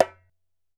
ASHIKO 4 0LR.wav